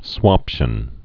(swŏpshən)